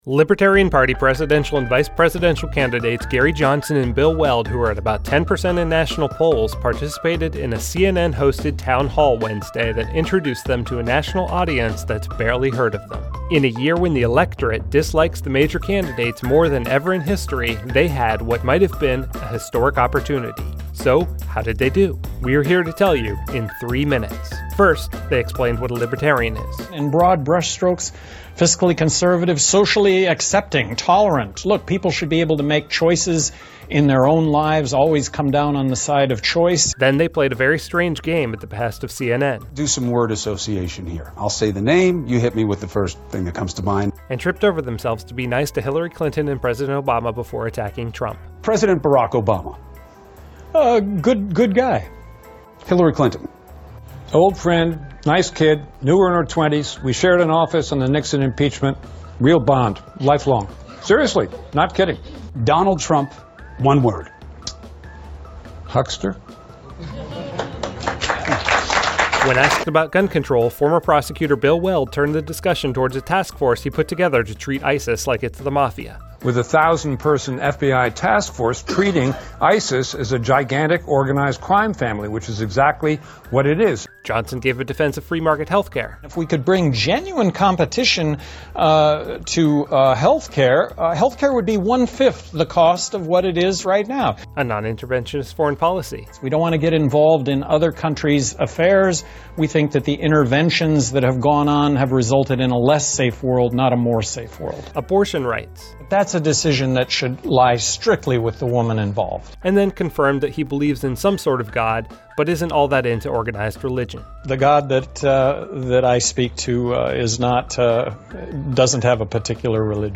Libertarian Party presidential and vice presidential candidates Gary Johnson and Bill Weld, who are polling around 10 percent in the national polls that include them, participated in a CNN-hosted town hall Wednesday that introduced them to a national audience that's barely heard of them.